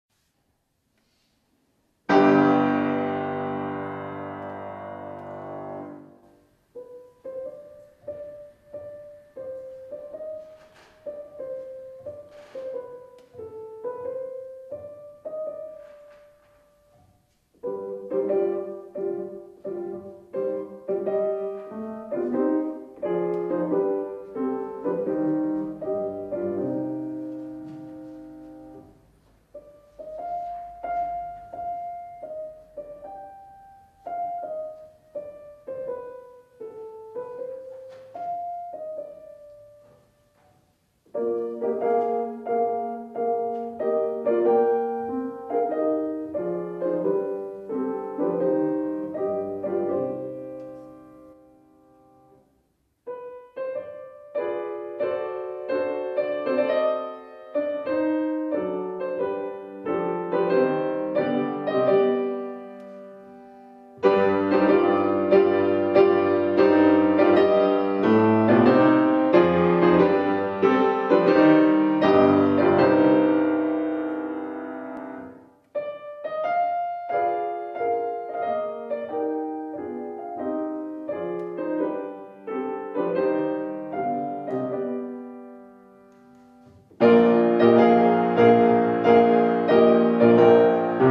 IV OTTOBRE MUSICALE A PALAZZO VALPERGA - Gli Otto Improvvisi di Franz Schubert
piano